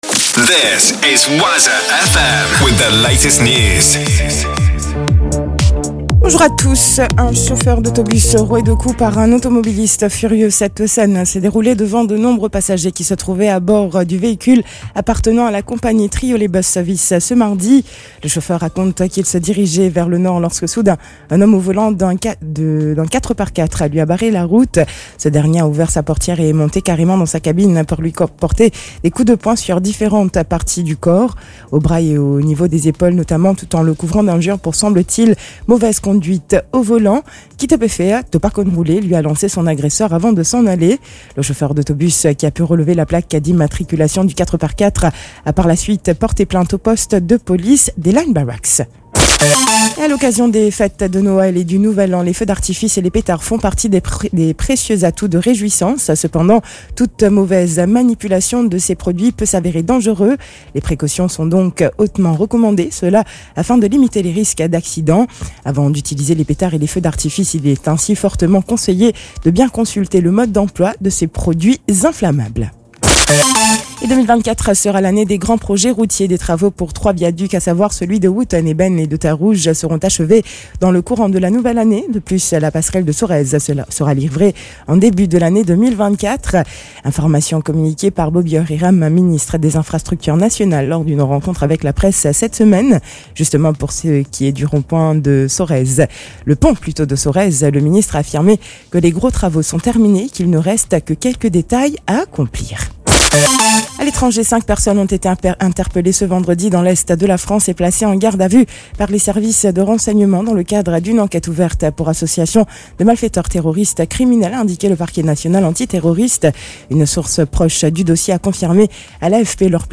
NEWS 7h - 23.12.23